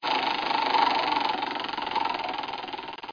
rotary.36bef9be.mp3